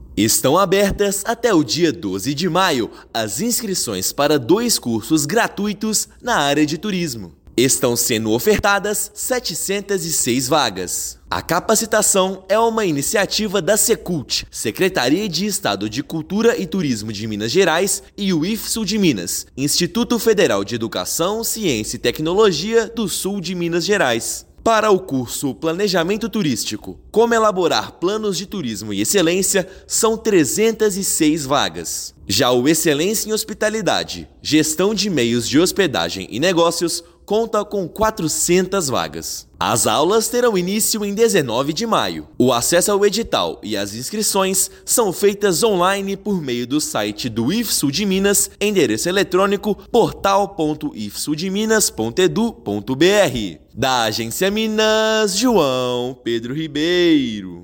Formações online são voltadas a interessados no setor e integram o programa Rotas do Conhecimento; inscrições vão até 12/5. Ouça matéria de rádio.